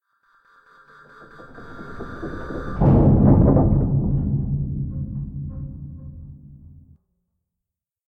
Commotion15.ogg